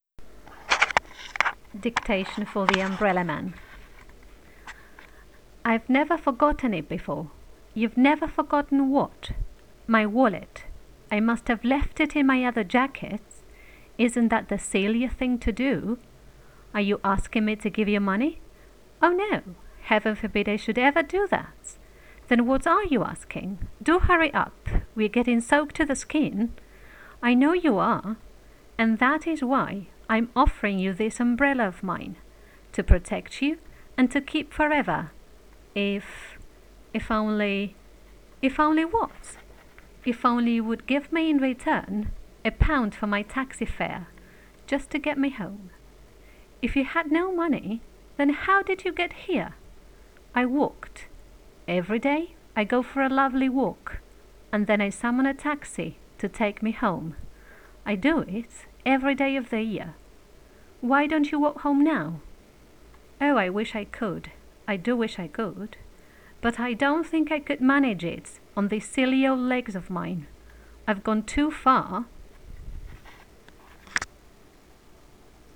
The Umbrella Man Dictation for intonation.wav